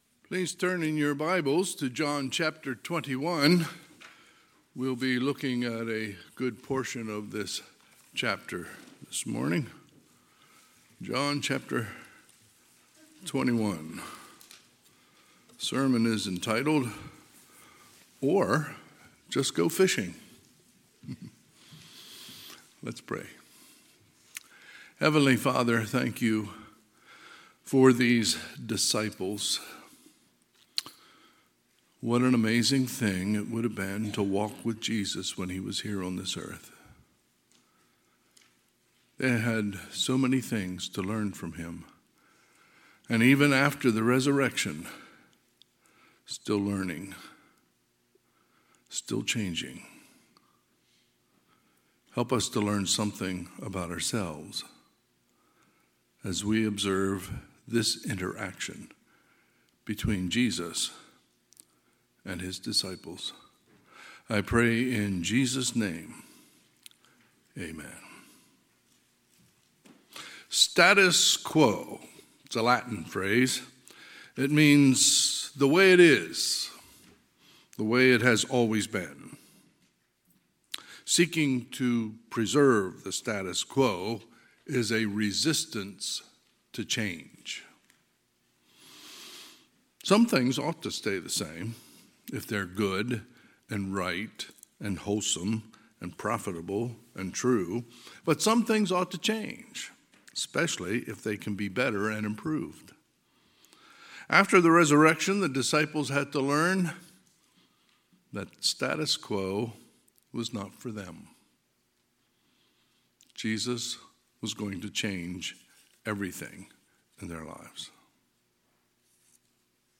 Sunday, April 23, 2023 – Sunday AM